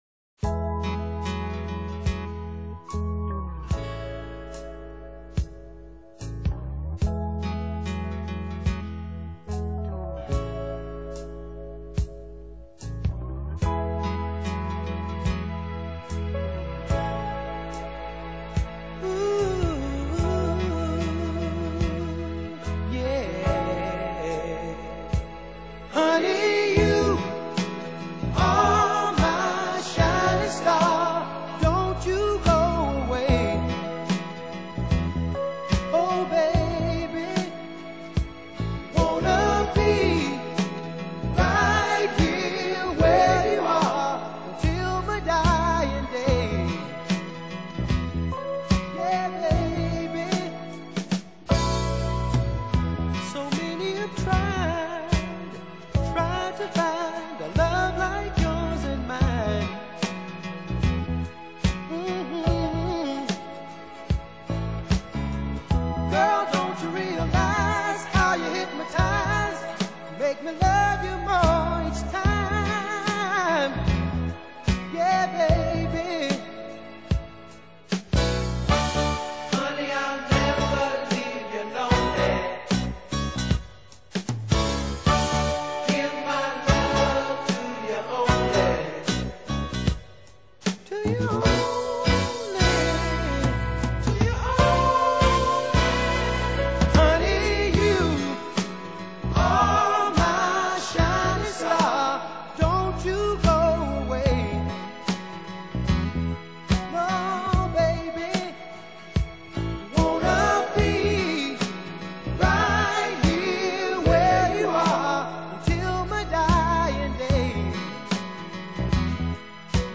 R&B/Soul | 1CD | Flac+CUE+LOG+HQ Scans (600 dpi) | 229 mb